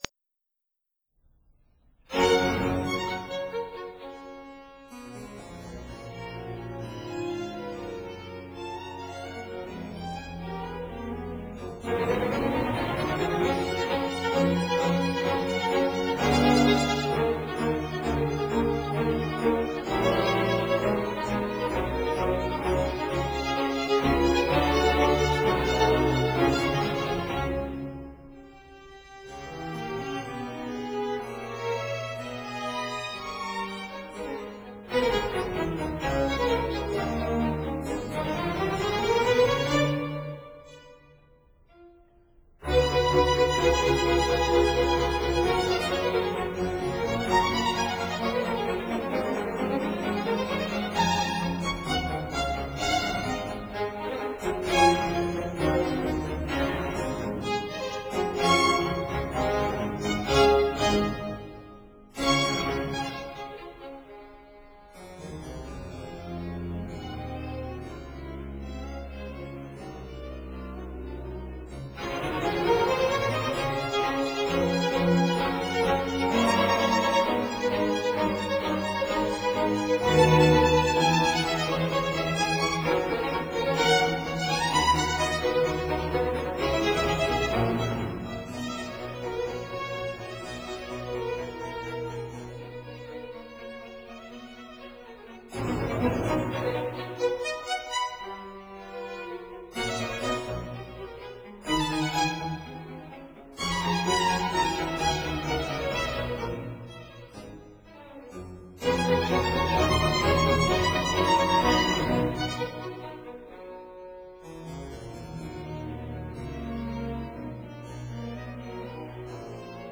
類型： 古典
Symphony in G major